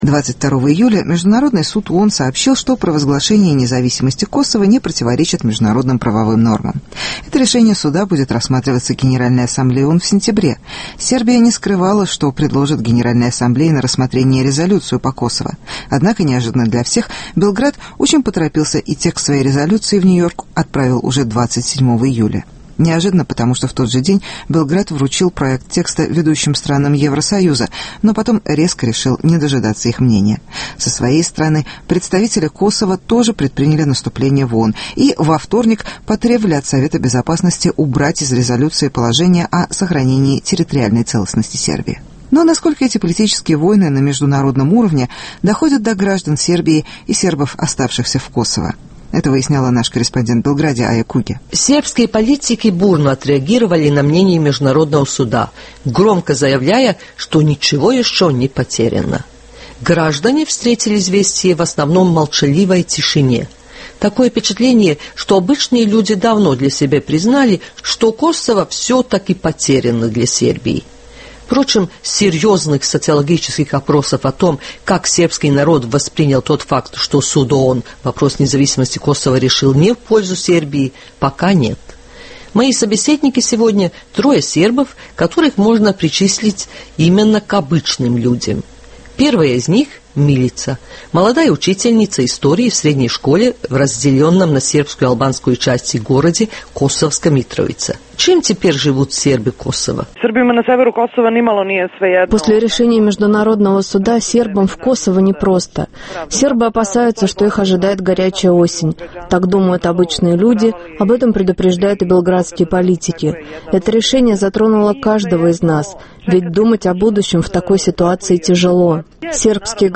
Опрос Радио Свобода: Белградцы о решении Международного суда по Косово.